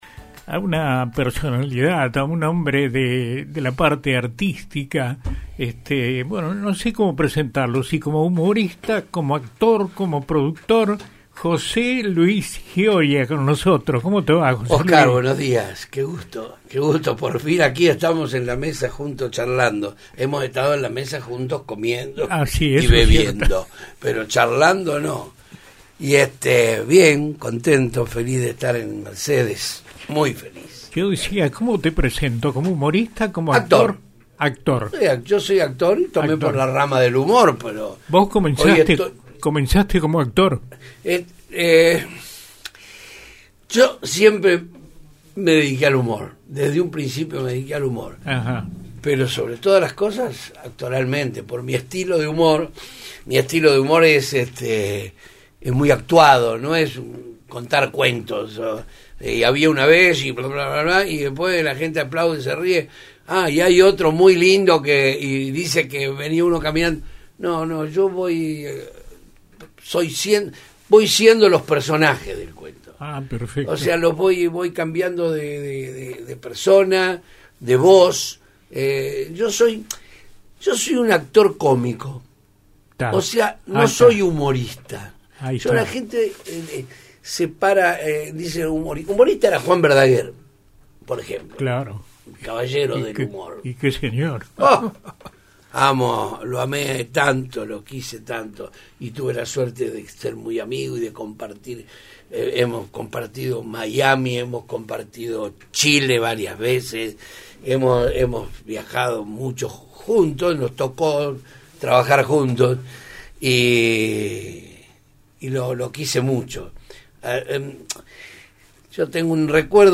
José Luis Gioia visito los estudios de Radio Universo | Universo Noticias